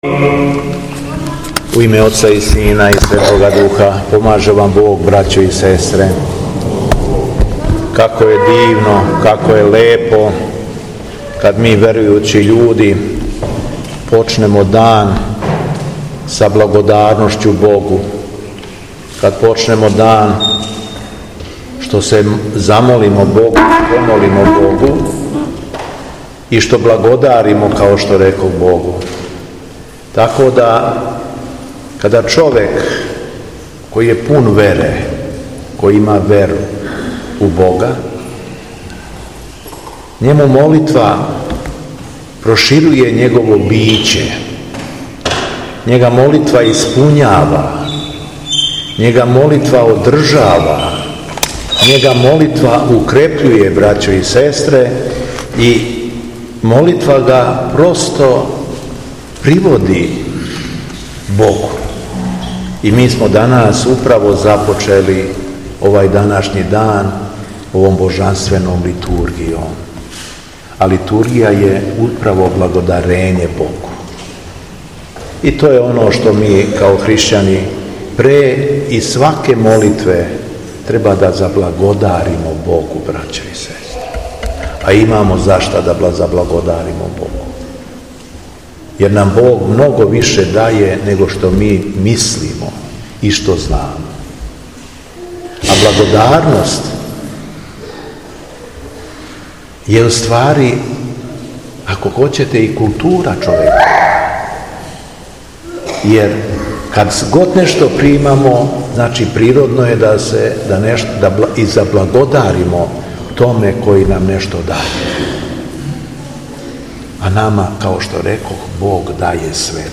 Беседа Његовог Преосвештенства Епископа шумадијског г. Јована
Надахнутом беседом шумадијски Првојерарх обратио се окупљеним верницима: